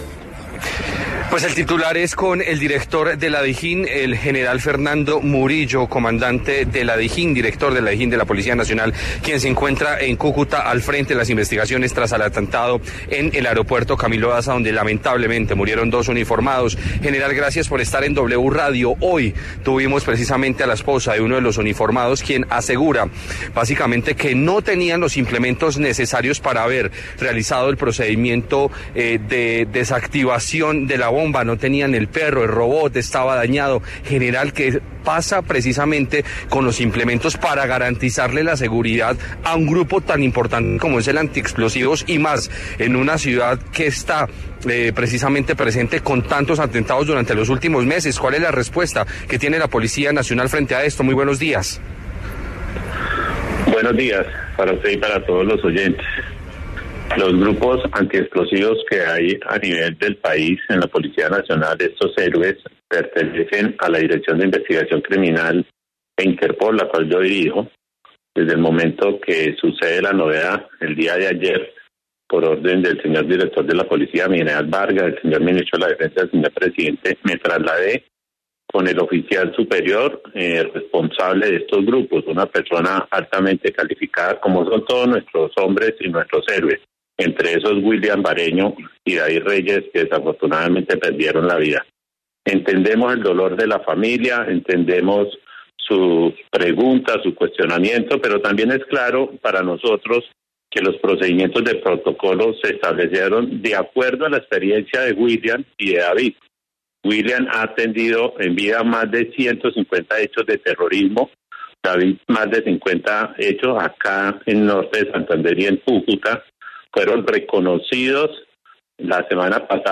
El director de la DIJÍN, Fernando Murillo, se refirió en La W a la denuncia de la esposa de uno de los intendentes fallecidos, quien sostiene que los Antiexplosivos no contaban con los equipos adecuados al desactivar la bomba en Cúcuta.